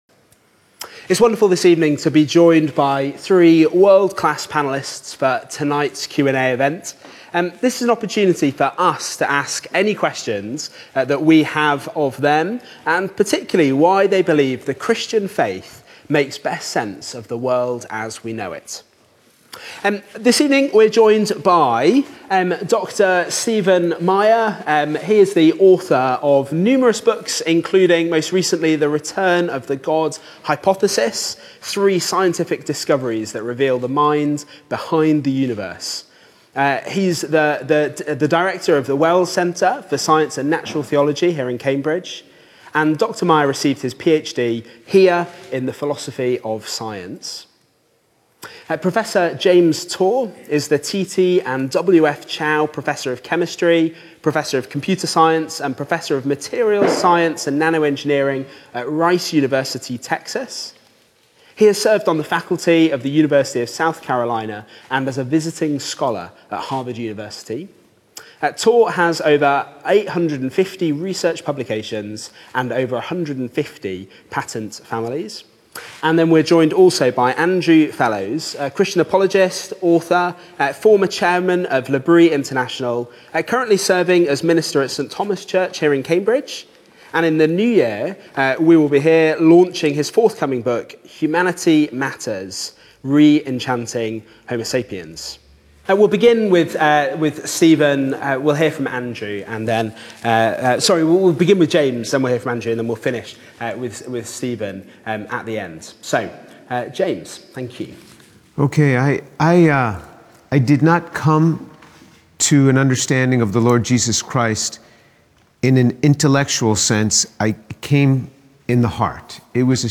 The panel fields audience questions on meaning, doubt, divine hiddenness, the reliability of mind, and the resurrection.